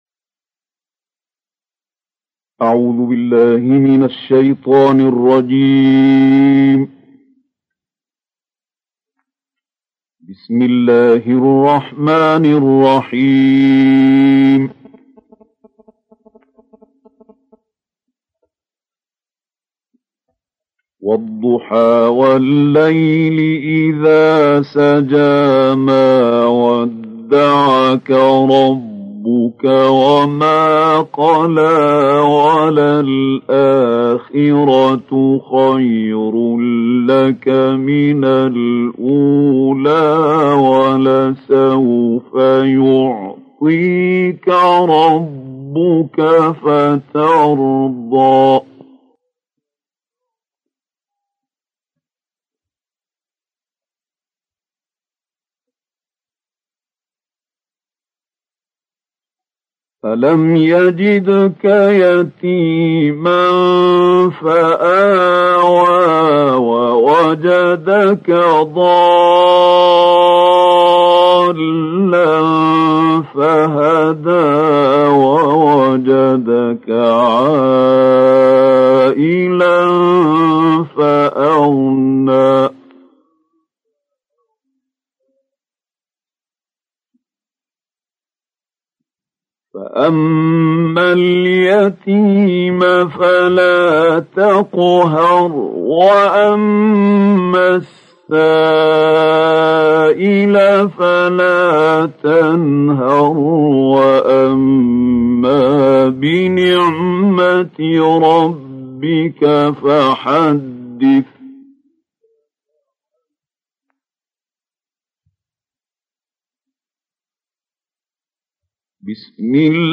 القارئ محمود خليل الحصري - قصار السور.